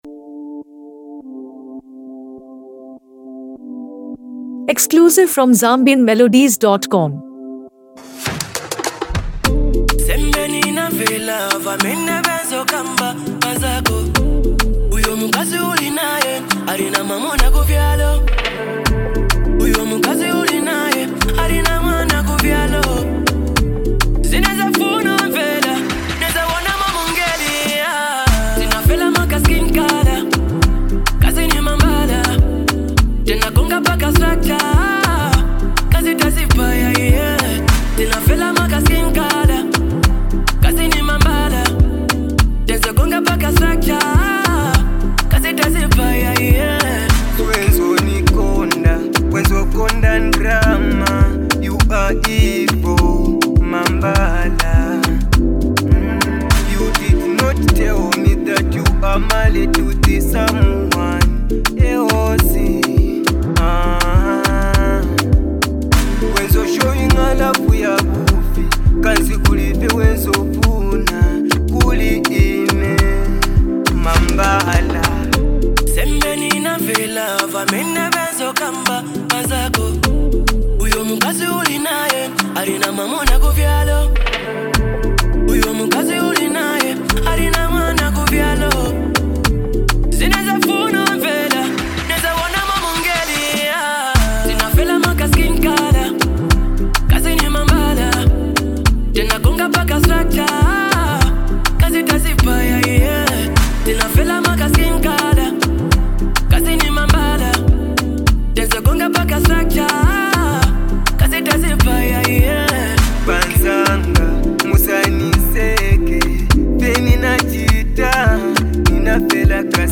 seasoned vocal tone and dynamic performance style